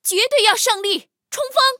黑豹夜战语音.OGG